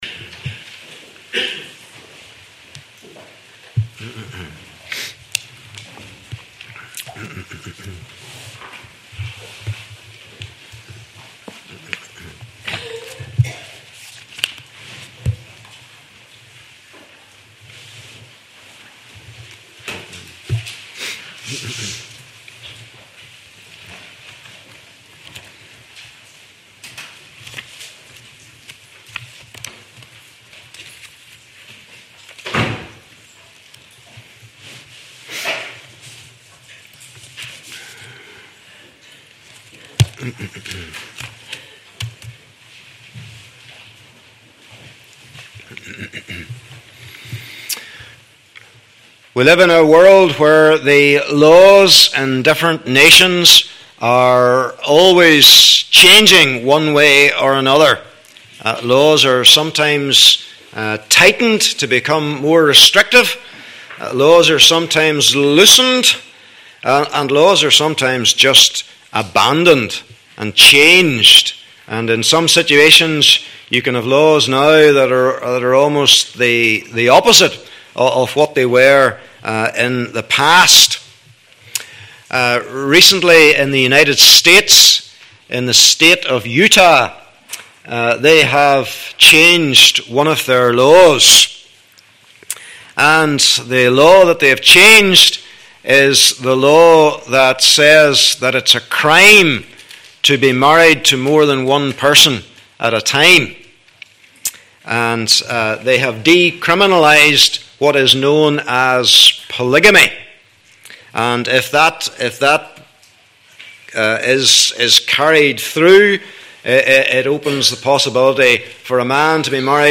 Passage: Exodus 19 : 1 - 20 : 2 Service Type: Morning Service